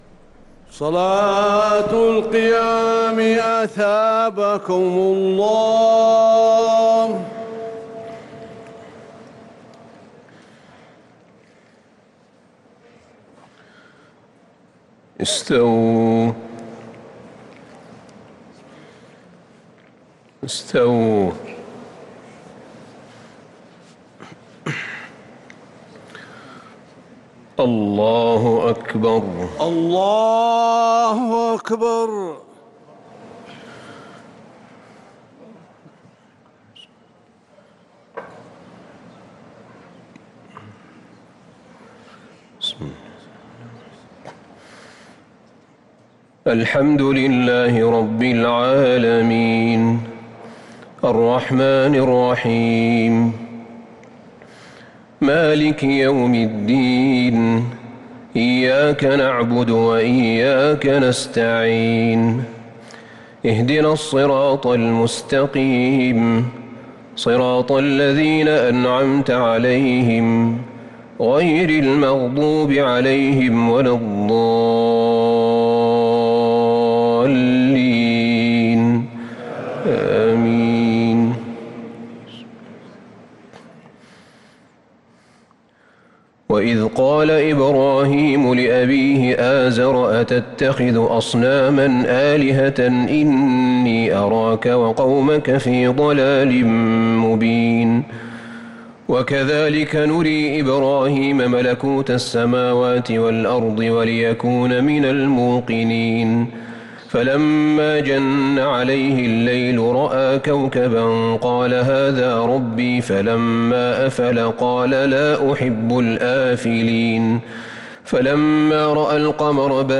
صلاة التراويح ليلة 10 رمضان 1443 للقارئ أحمد بن طالب حميد - الثلاث التسليمات الأولى صلاة التراويح